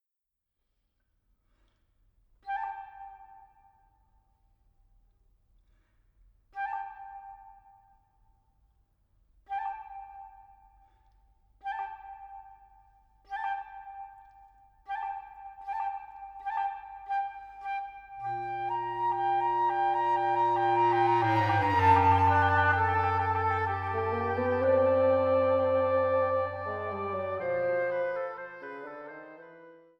Sopran
Flöte, Altflöte
Oboe, Englischhorn
Bassetthorn
Fagott